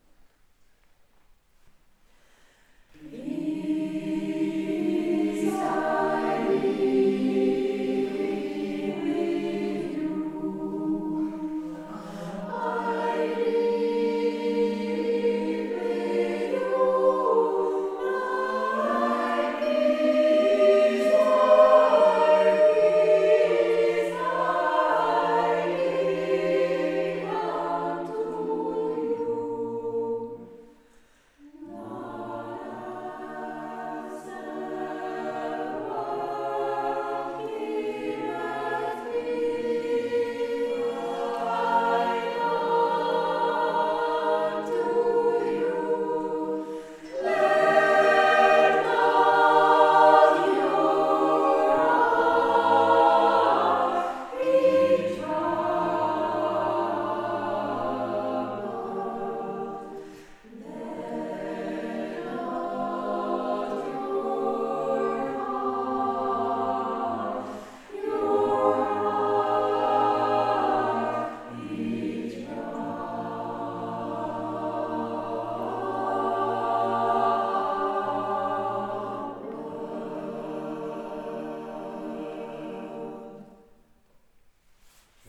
Seit 2018 ist die SingWerkstatt ein gemischter Chor, wobei die Herren erst im Januar 2024 Geschmack an uns gefunden haben.